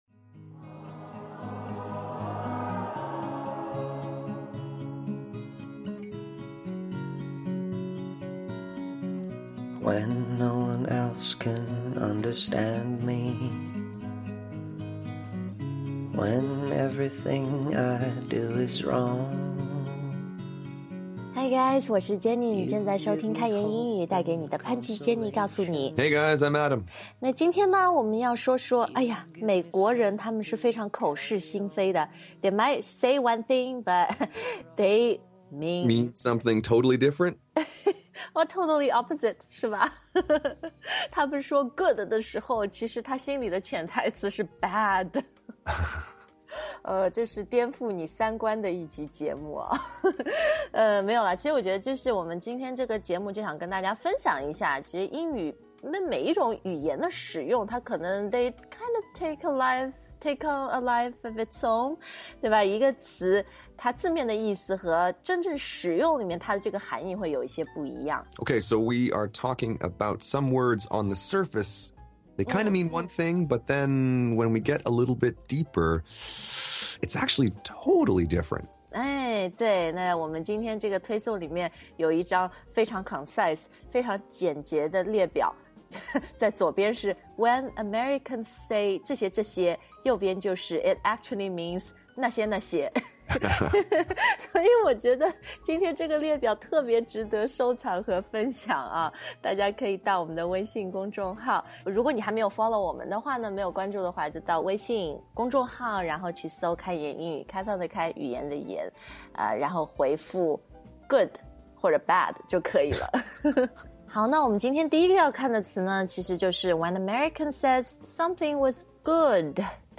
朗诵
专业诵读 精良制作